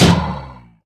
sounds / mob / irongolem / repair.ogg
repair.ogg